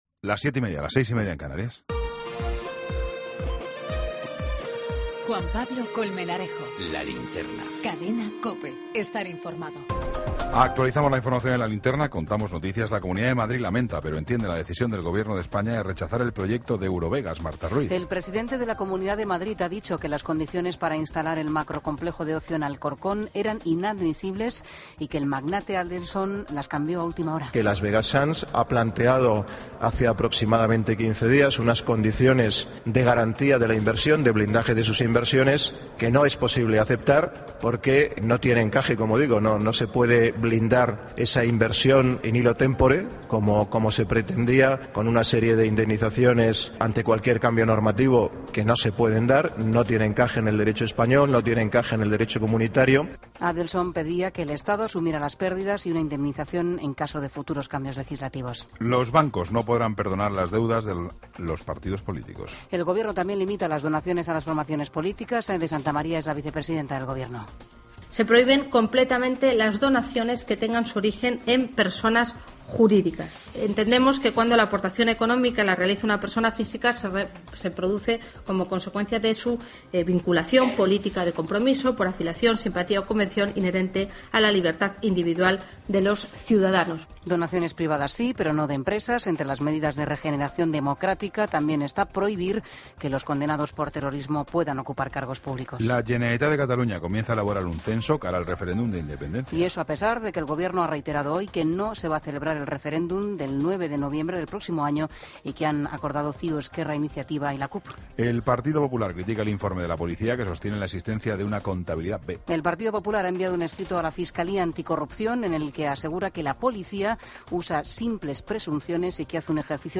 AUDIO: Toda la información con Juan Pablo Colmenarejo. Entrevista